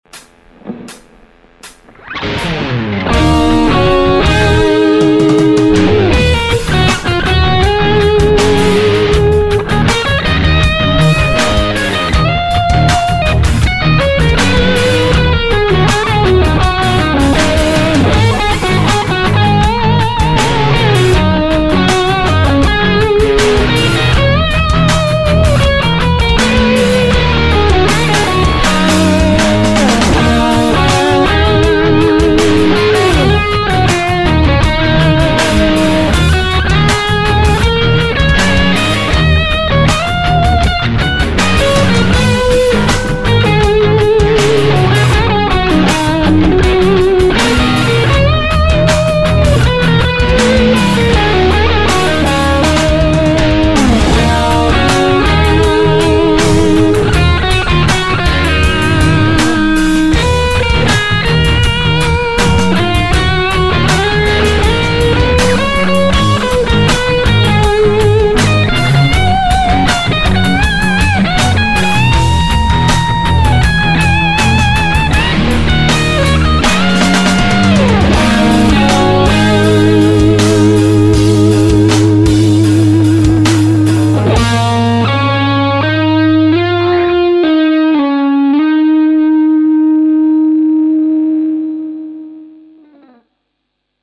ich habe heute mal zottel-zwo ins Studio gestellt, mein altes Sennheiser davorgepackt und mal schnell nen Take aufgenommen, war halt gespannt, wie er sich so macht, das Ergebnis gibt es hier: